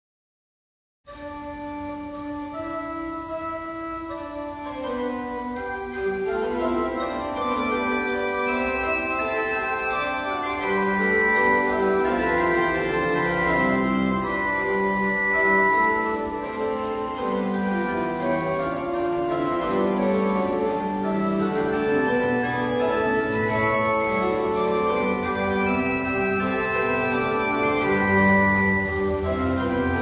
Musiche strumentali italiane del Seicento e del Settecento